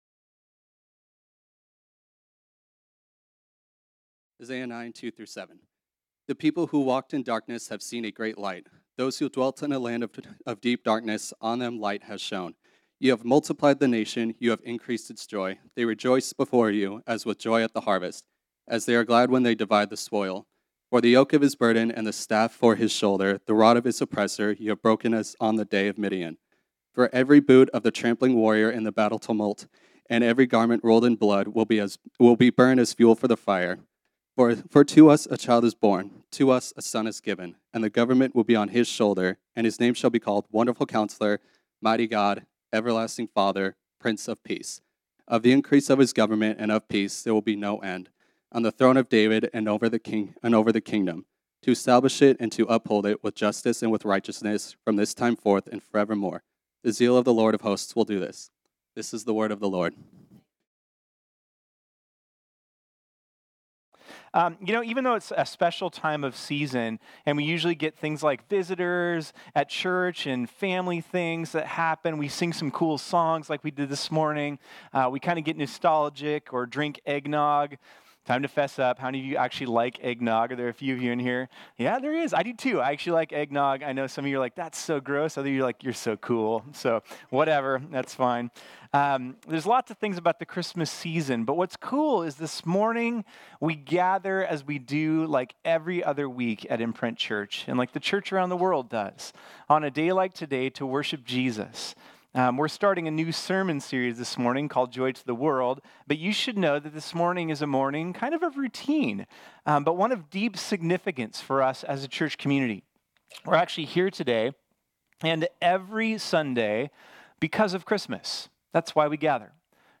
This sermon was originally preached on Sunday, December 8, 2019.